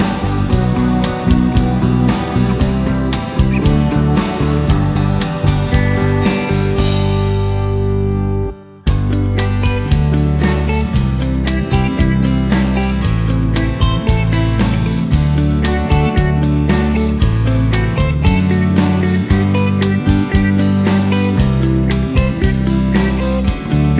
no Backing Vocals Glam Rock 3:59 Buy £1.50